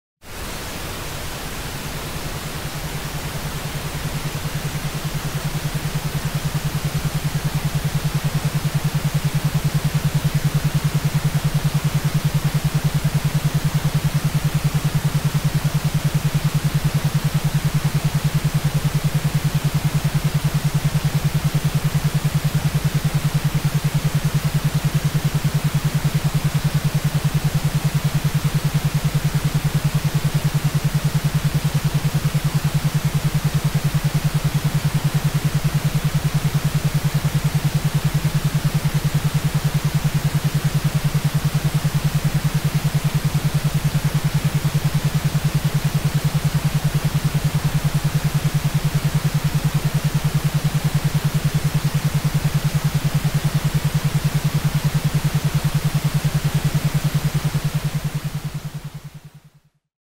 • הרצועה השנייה: משלבת את אותם תדרים איזוכרונים לקשב וריכוז עם רעש ורוד.
בספקטרום השמיעתי הוא נשמע מעט עמום יותר מרעש לבן או חום.
דוגמה להאזנה תדרים איזוכרוניים לפוקוס ריכוז וקוגניציה עם רקע רעש ורוד:
רצועת אימון גלי מוח לפוקוס עם רעש ורוד